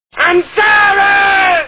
Pee Wee's Play House TV Show Sound Bites